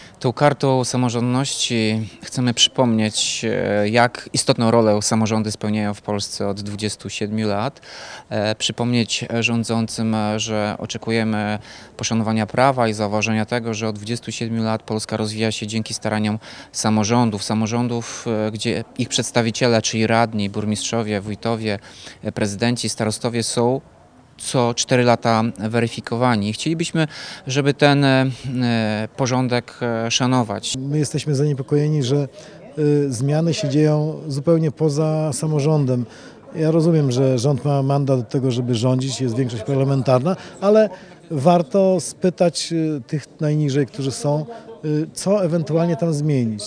Po co taką Kartę warto przyjmować tłumaczy Tomasz Andrukiewicz, prezydent Ełku i Marek Chojnowski starosta powiatu ełckiego.